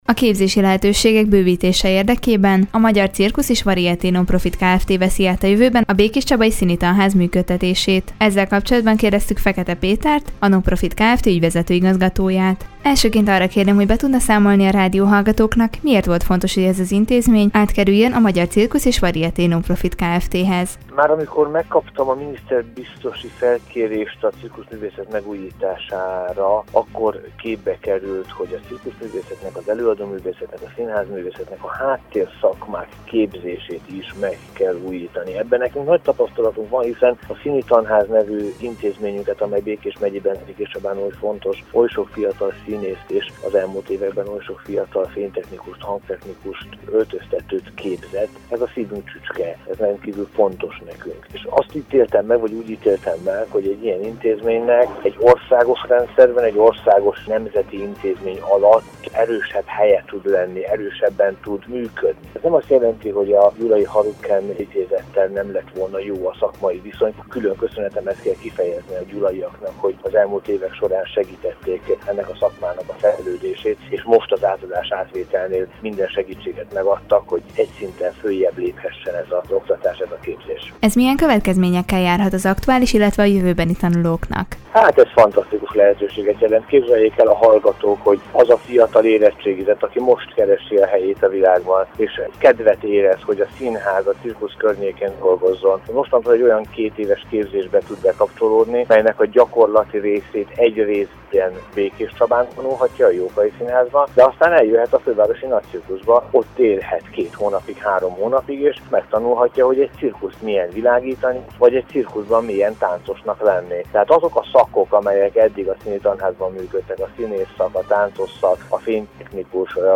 A Magyar Cirkusz és Varieté Nonprofit Kft. veszi át a Békéscsabai Színitanház működtetését. Ezzel kapcsolatban beszélgetett tudósítónk Fekete Péterrel, a Nonprofit Kft. ügyvezető igazgatójával.